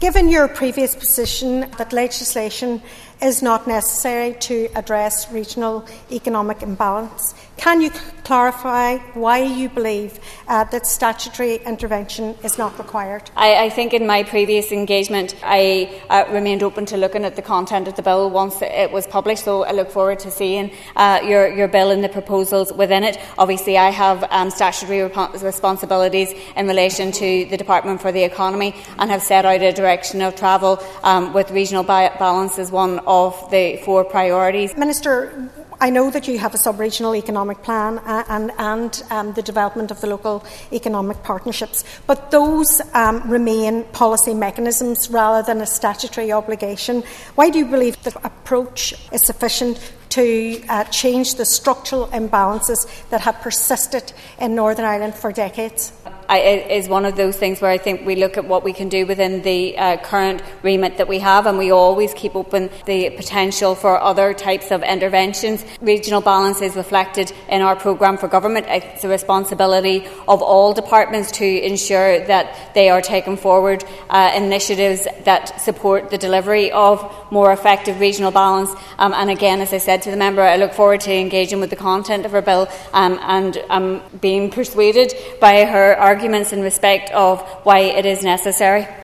Foyle MLA Sinead McLaughlin told the Assembly this afternoon she has received the first copy of a bill she is putting before the Assembly calling for regional balance to be made a statutory requirement.
Questioning Minister Caoimhe Archibald, Mc McLaughlin asked of she still believes that regional balance does not need to be legislated for……..